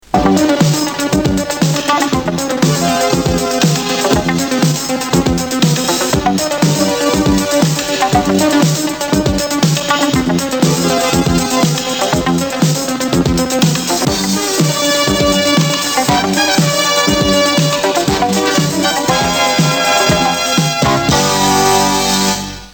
Générique de fin